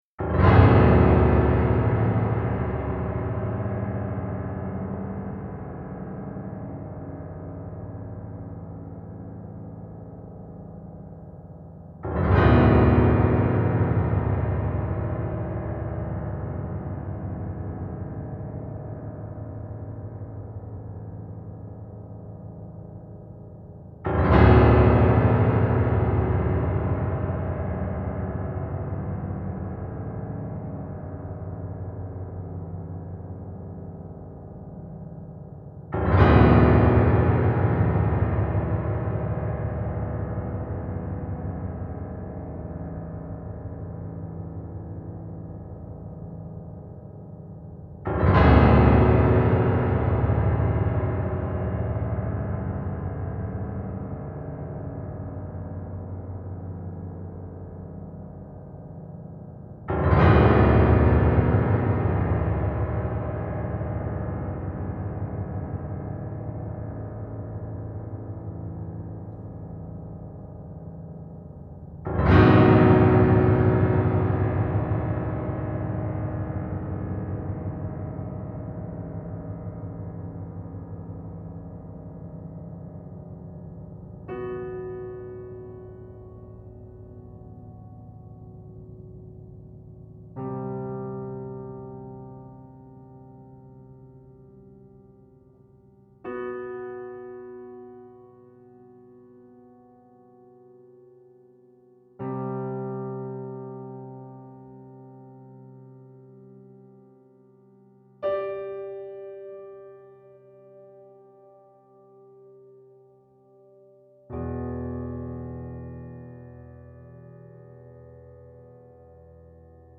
piano Duration